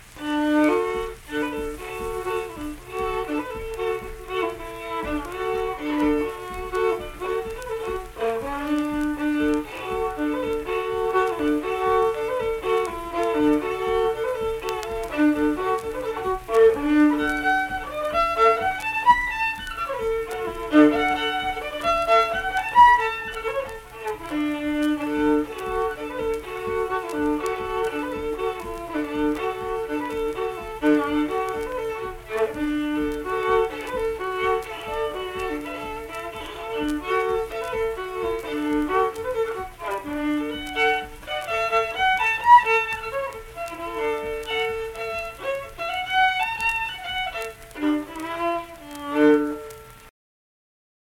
Unaccompanied fiddle performance
Instrumental Music
Fiddle
Tyler County (W. Va.), Middlebourne (W. Va.)